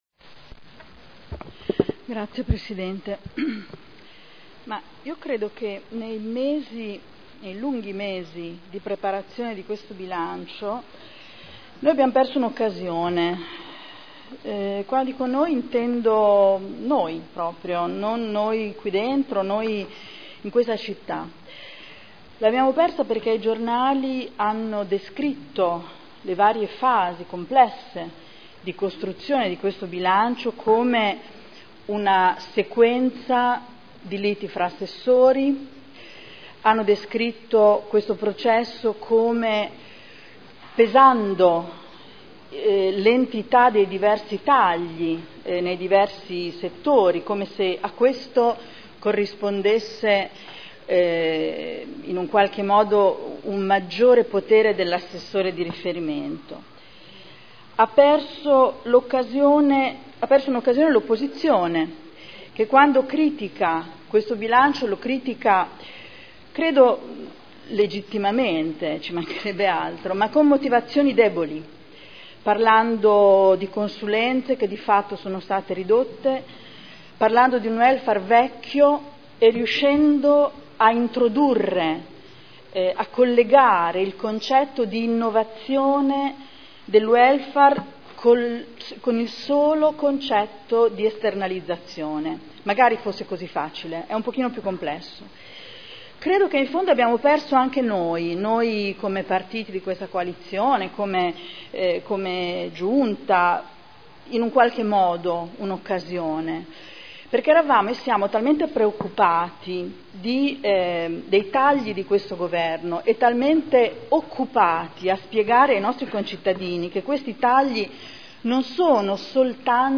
Seduta del 28/03/2011. Dibattito sul Bilancio.